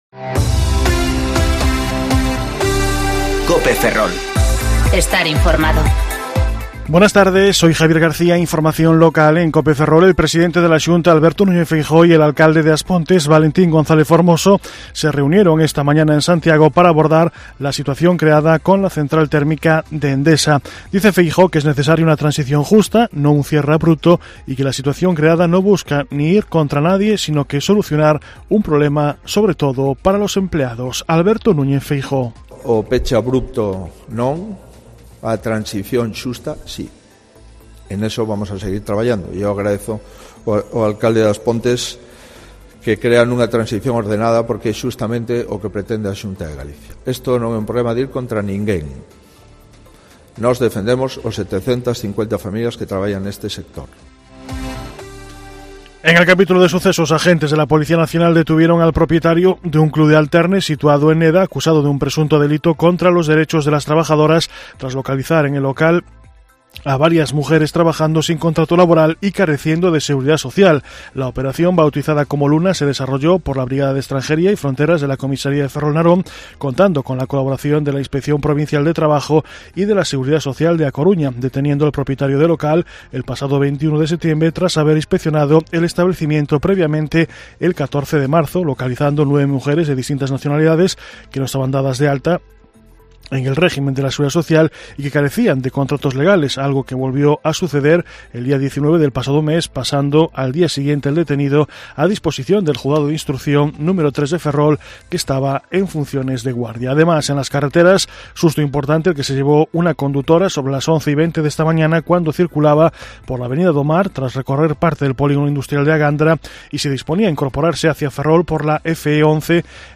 Informativo Mediodía Cope Ferrol 7/10/2019 (De 14.20 a 14.30 horas)